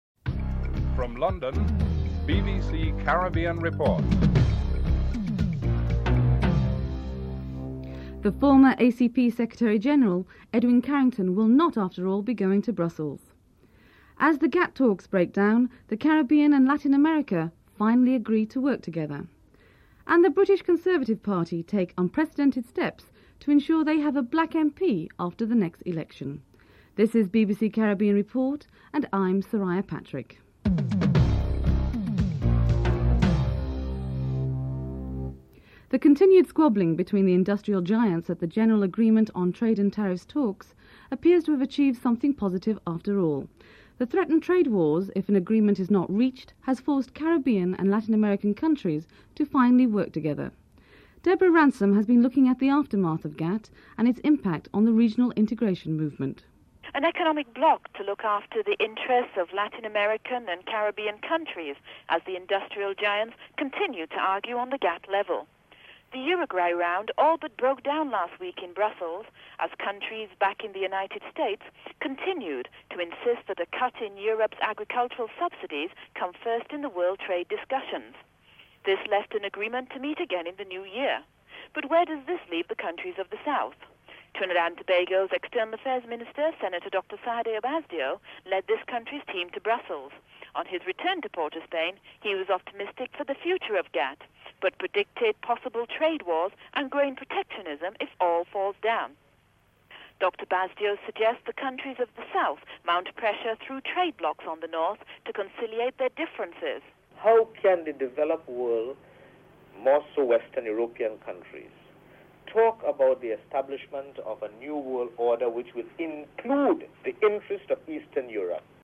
1. Headlines (00:00-00:34)
Interview with the team’s captain, Desmond Haynes (10:52-12:39)